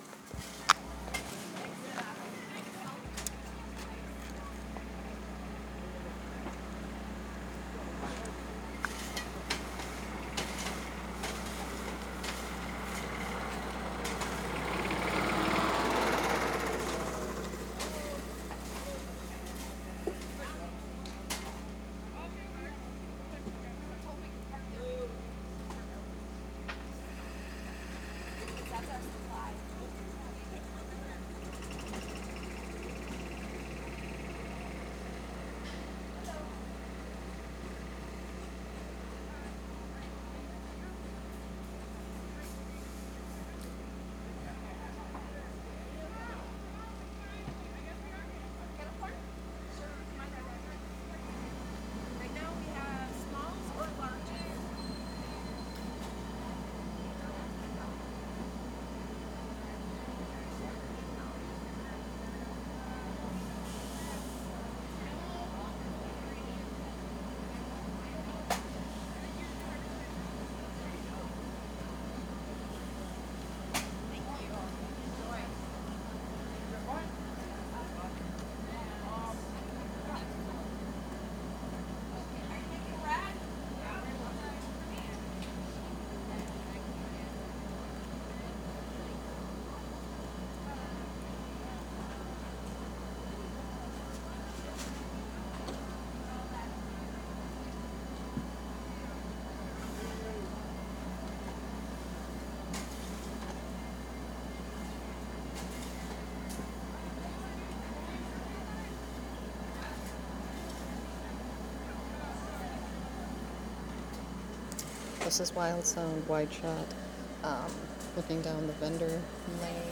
Menominee PowWow 4 Aug 2023 Vendor Lane copy.wav